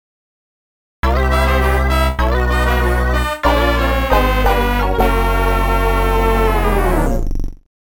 The fanfare